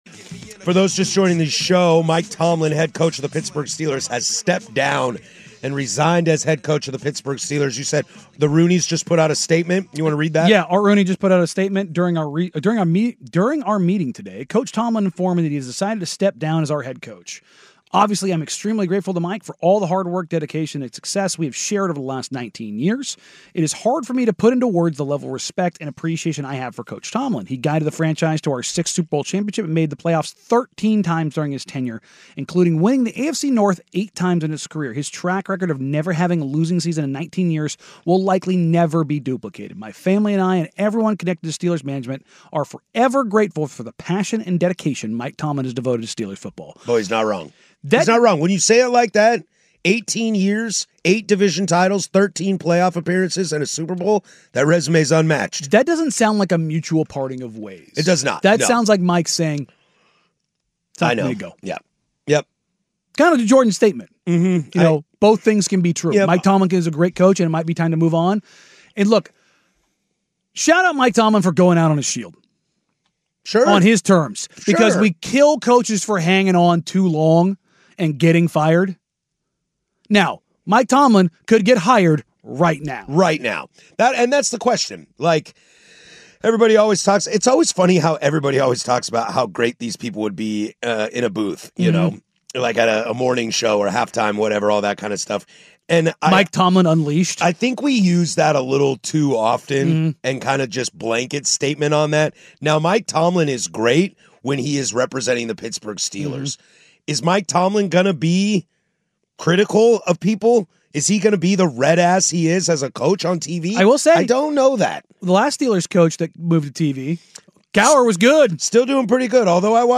Fast paced and local, giving in depth insights to the Trail Blazers, baseball, college football and the NFL.